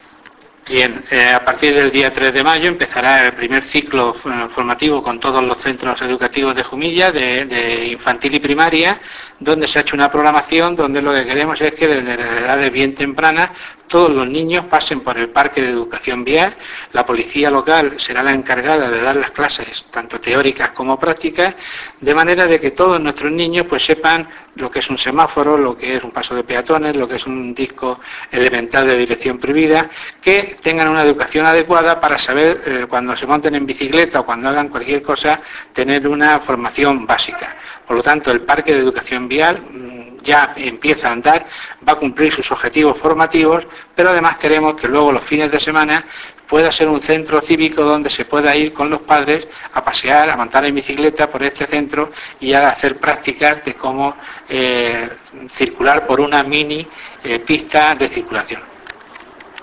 Descargar: Primitivo González habla de estas jornadas subir